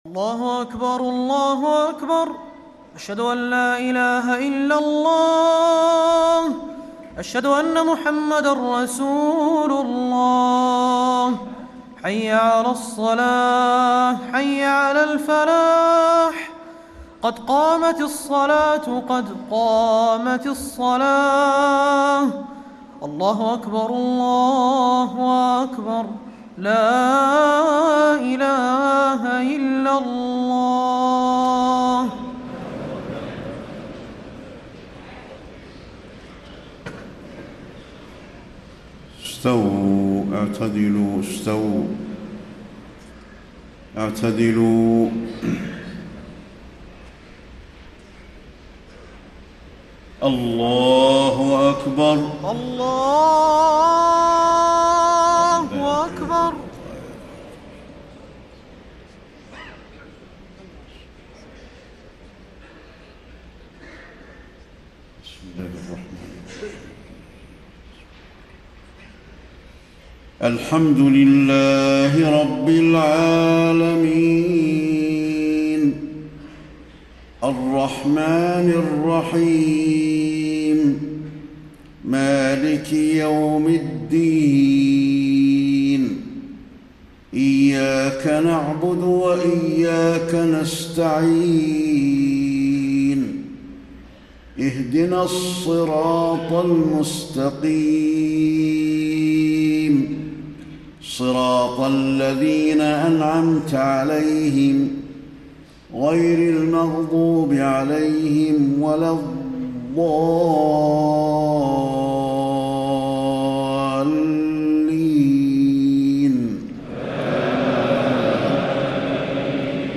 صلاة العشاء 5-5-1434 سورتي الفجر و الهمزة > 1434 🕌 > الفروض - تلاوات الحرمين